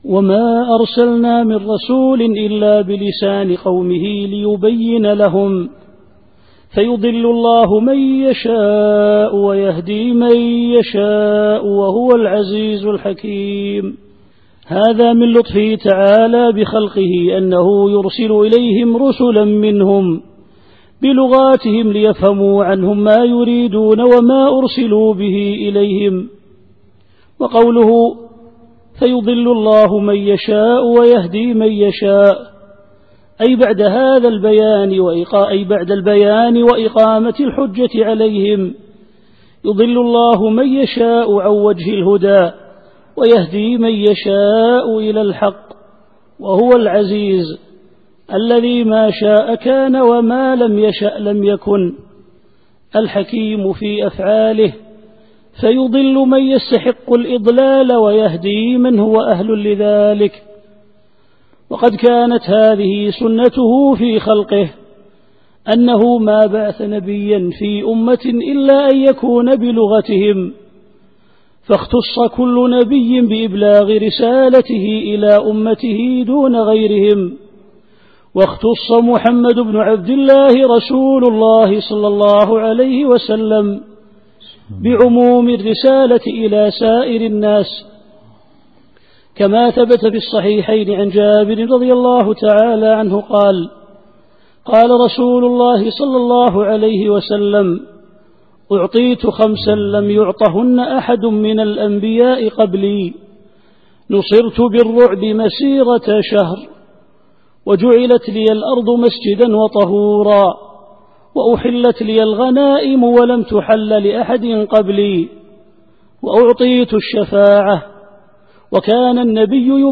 التفسير الصوتي [إبراهيم / 4]